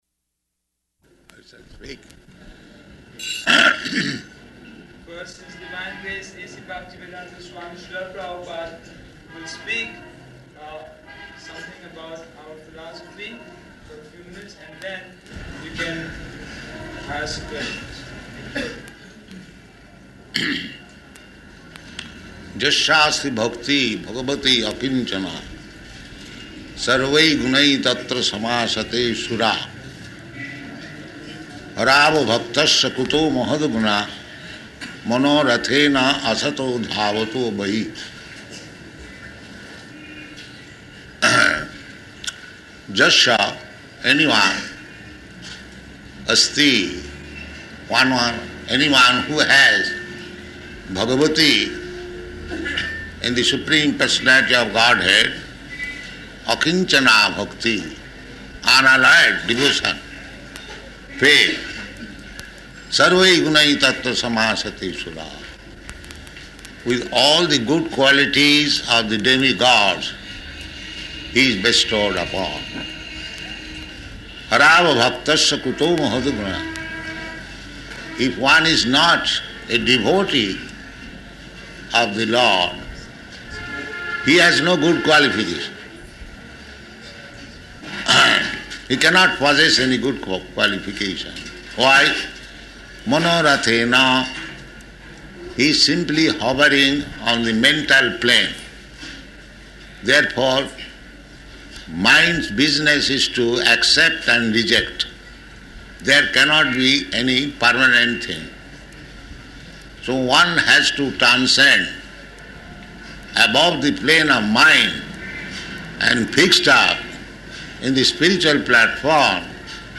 Press Conference
Press Conference --:-- --:-- Type: Conversation Dated: April 18th 1974 Location: Hyderabad Audio file: 740418PC.HYD.mp3 Prabhupāda: ...I shall speak?